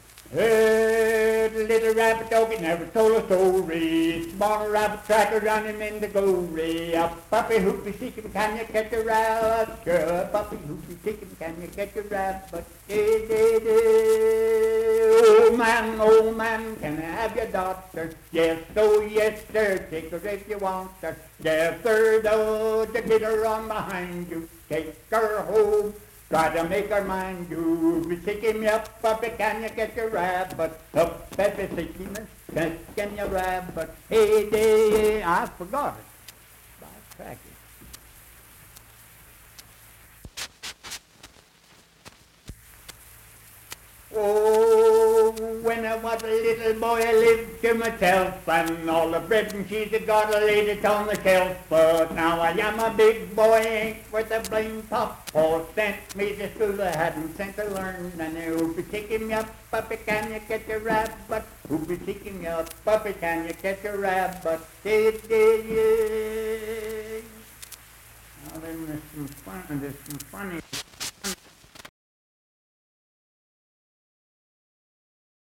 Unaccompanied vocal music performance
Minstrel, Blackface, and African-American Songs
Voice (sung)